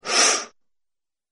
Man Pain Gasp